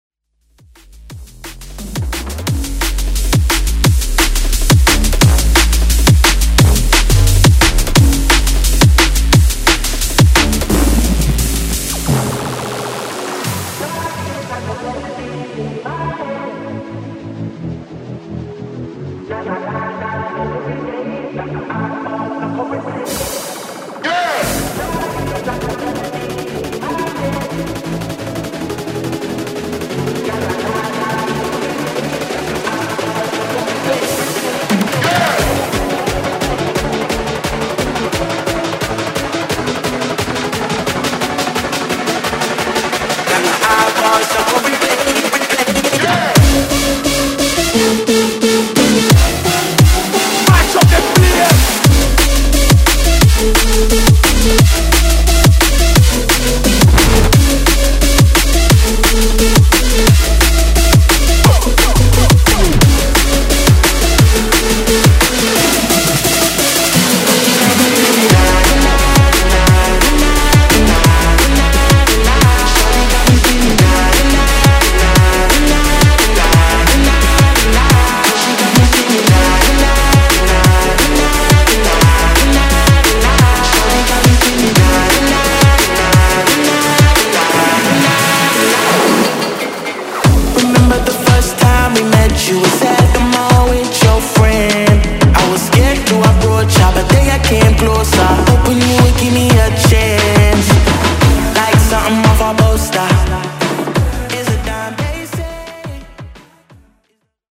HIPHOP , TOP40 Version: Clean BPM: 102 Time